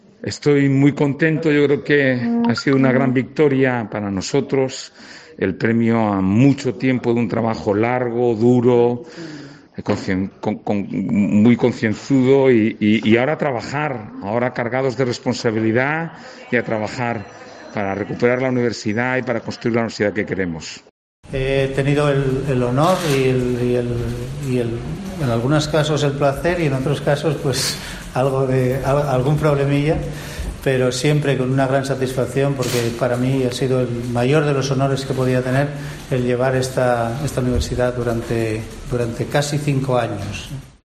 Ignacio Villaverde y Santiago García Granda valoran el resultado de las elecciones